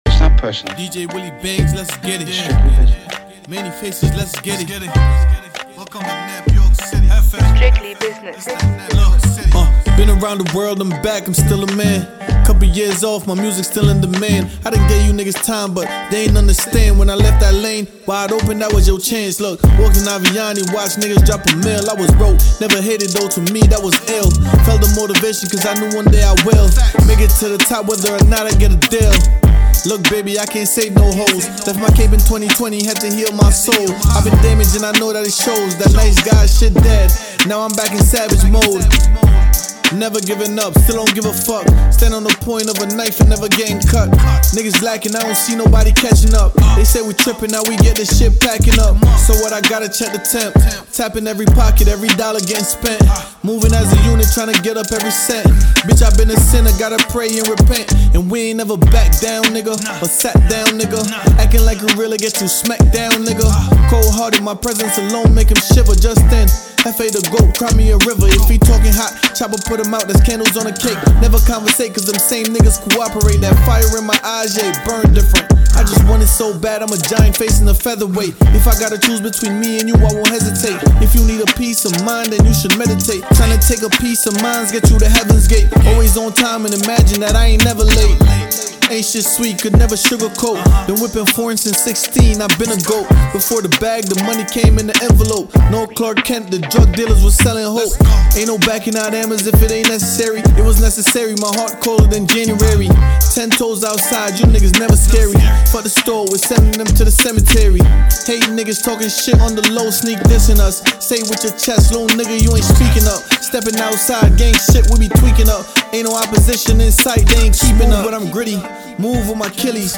Genre: Hip Hop.